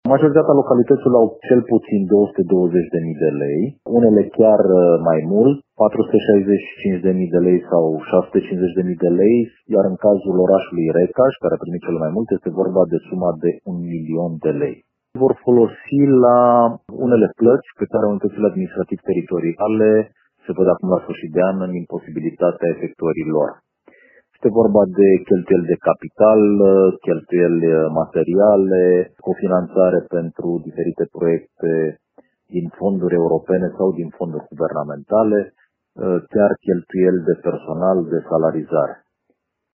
Potrivit subprefectului de Timiș, Ovidiu Drăgănescu, cea mai mare sumă o cere Primăria Recaș: